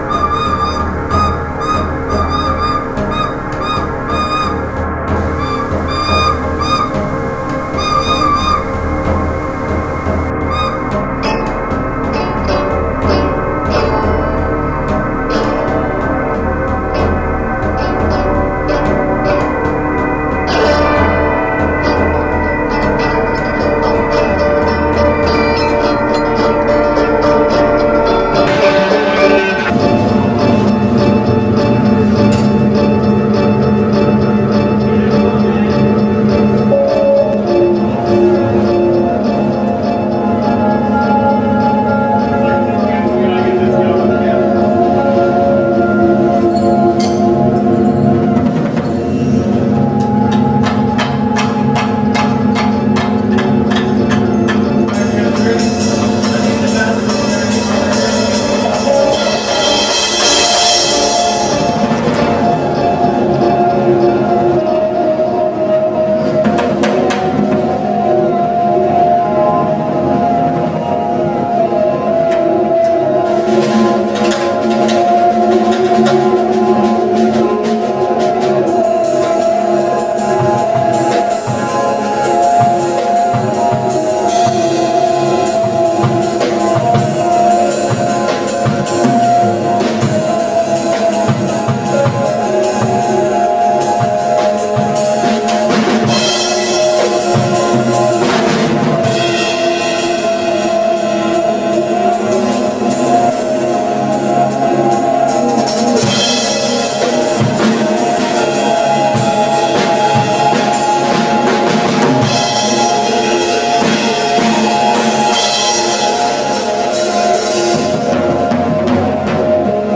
genre: experimental